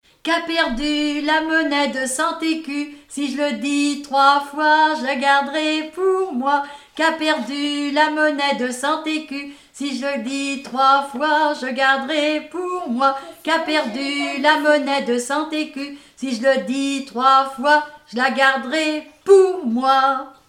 Enfantines - rondes et jeux
enfantine : comptine
Pièce musicale inédite